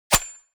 GrappleHit.wav